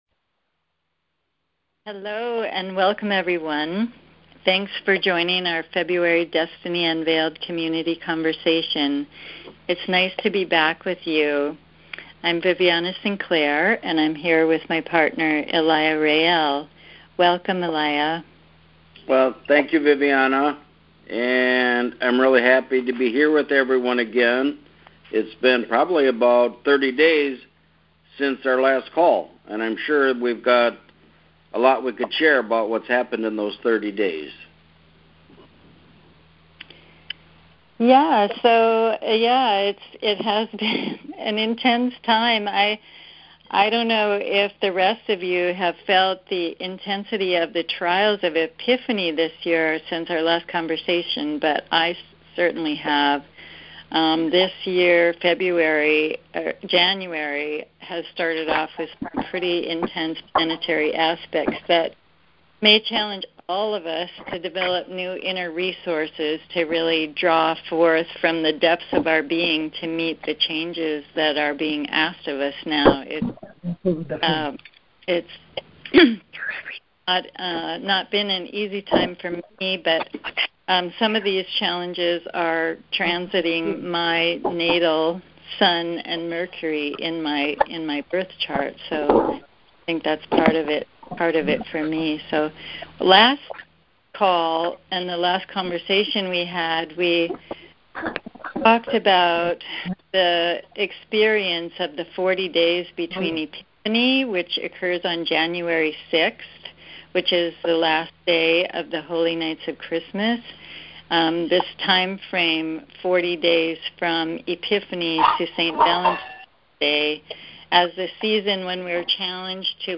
Monday Community Conversation Audio Recordings and PDF Offerings